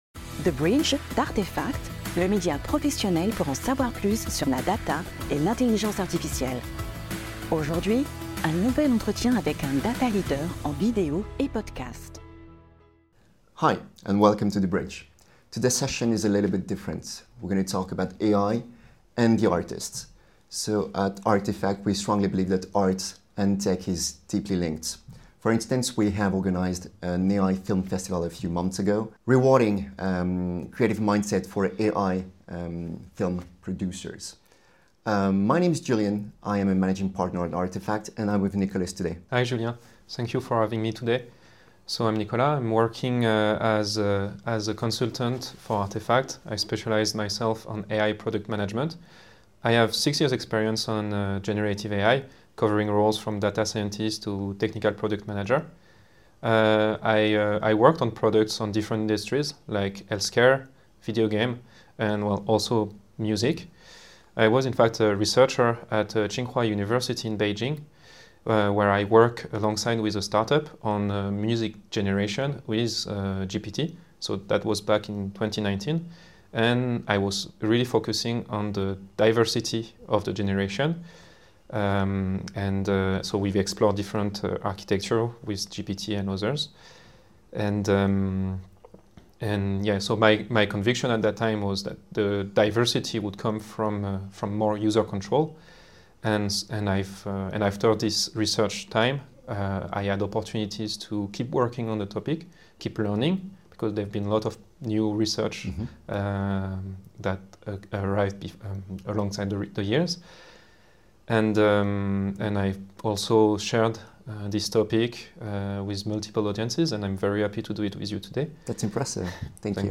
A conversation about Artificial Intelligence in music and creativity - The Bridge by Artefact
The-Bridge-by-Artefact-A-conversation-about-AI-music-creativity.mp3